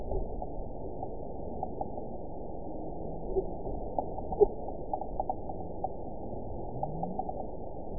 event 912558 date 03/29/22 time 13:16:06 GMT (3 years, 1 month ago) score 9.59 location TSS-AB05 detected by nrw target species NRW annotations +NRW Spectrogram: Frequency (kHz) vs. Time (s) audio not available .wav